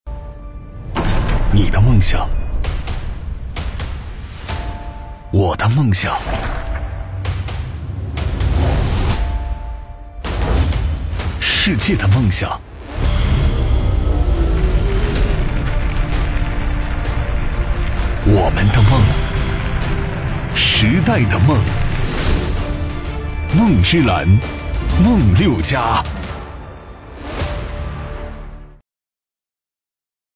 男3-梦之蓝品牌广告mp3.mp3.mp3